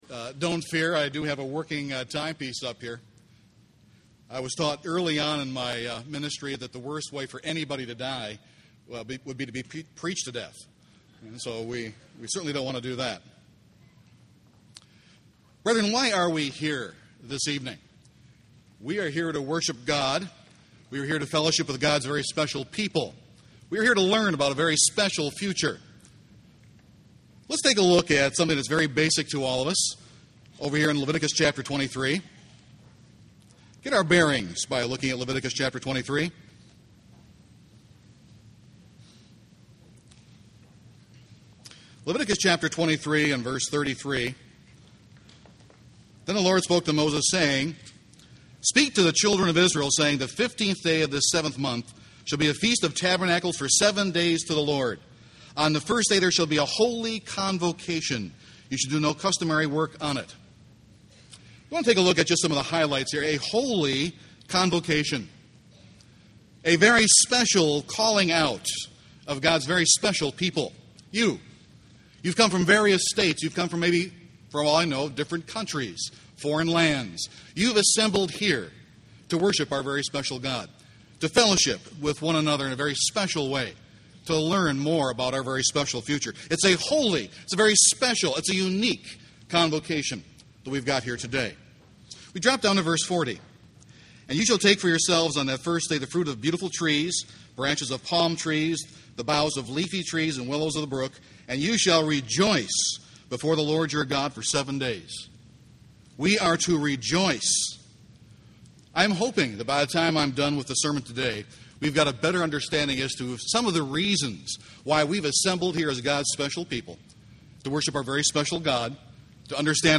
This sermon was given at the Wisconsin Dells, Wisconsin 2007 Feast site.